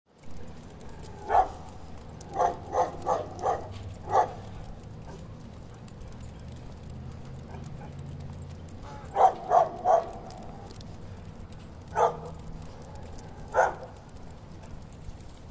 Barking dog: 0 Dog pee: 15 seconds